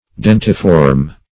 Search Result for " dentiform" : The Collaborative International Dictionary of English v.0.48: Dentiform \Den"ti*form\, a. [L. dens, dentis, tooth + -form: cf. F. dentiforme.] Having the form of a tooth or of teeth; tooth-shaped.